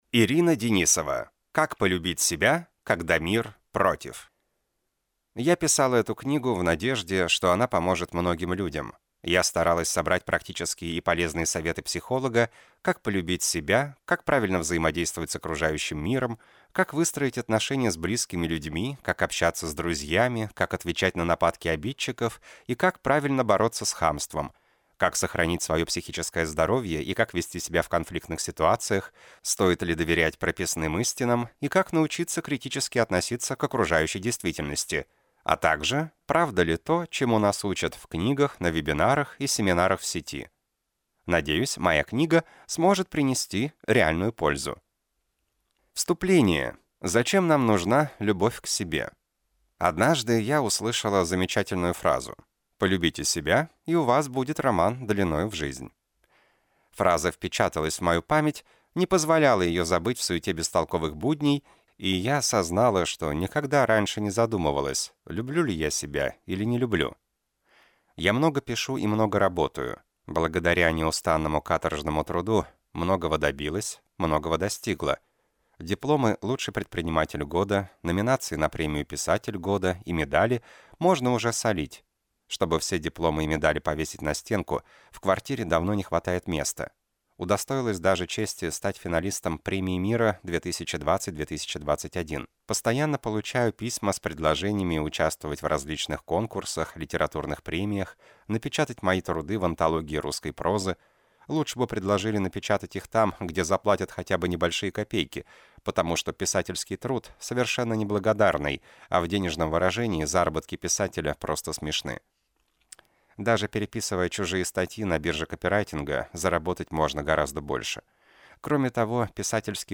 Аудиокнига Как полюбить себя, когда мир против | Библиотека аудиокниг